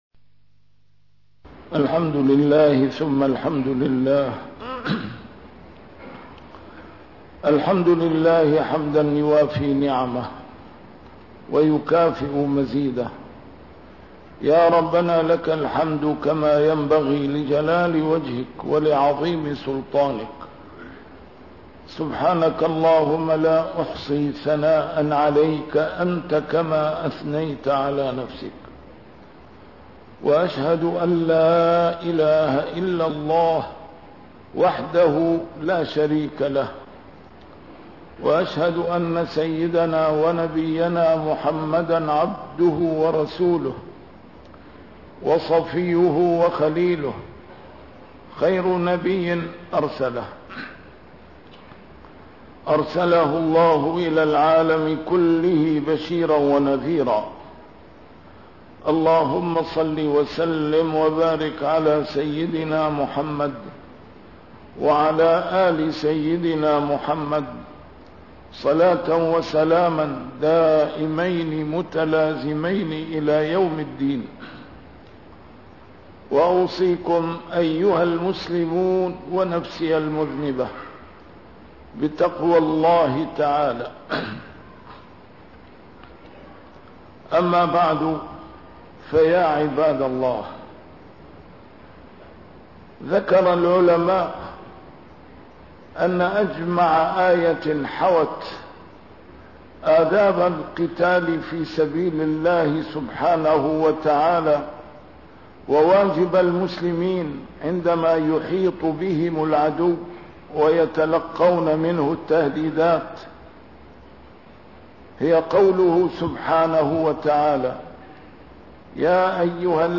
A MARTYR SCHOLAR: IMAM MUHAMMAD SAEED RAMADAN AL-BOUTI - الخطب - رأس مالنا للنصر هو الالتجاء إلى الله